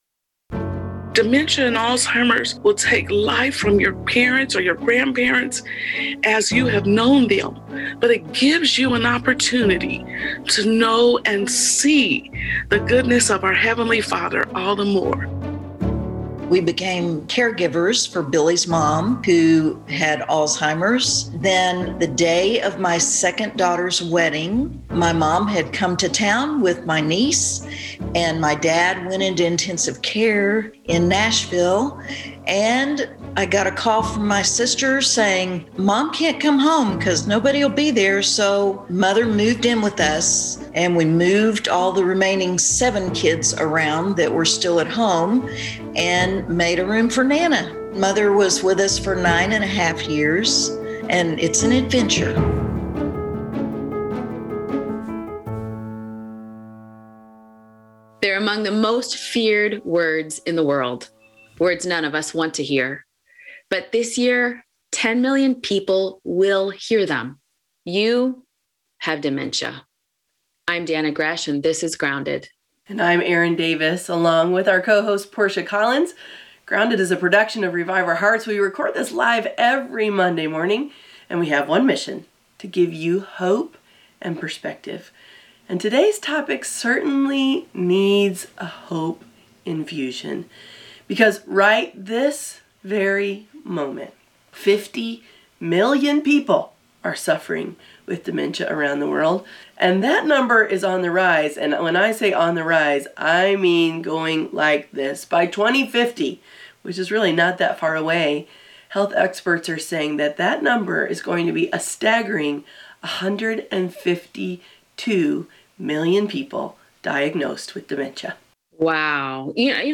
We record this live every Monday morning.